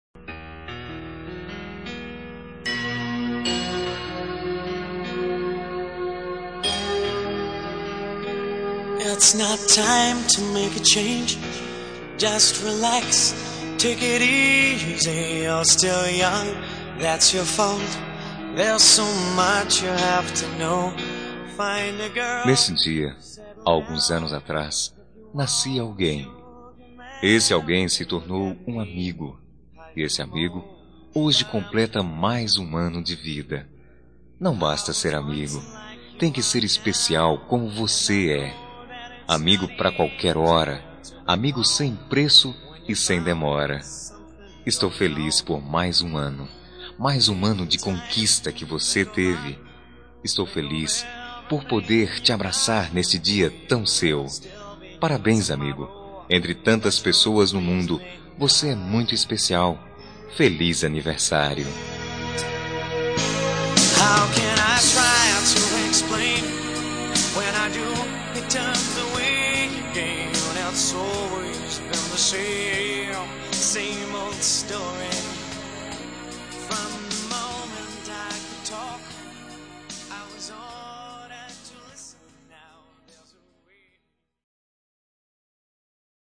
Telemensagem de Aniversário de Amigo – Voz Masculina – Cód: 1610